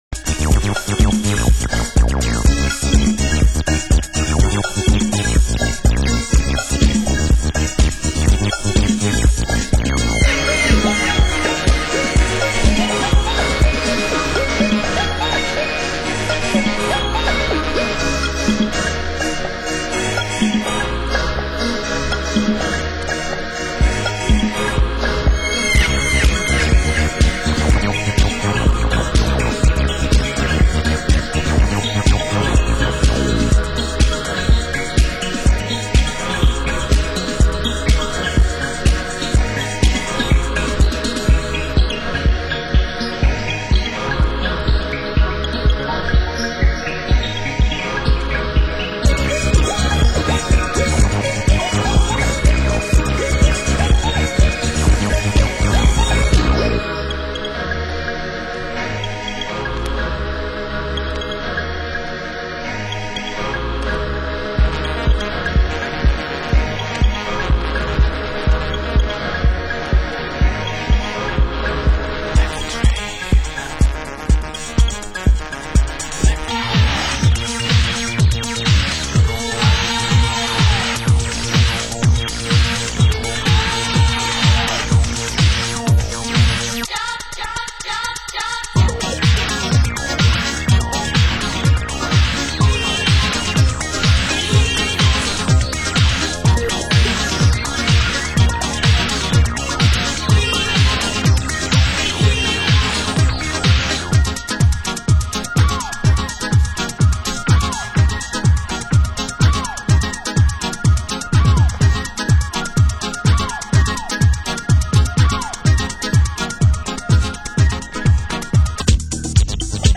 Genre: Acid House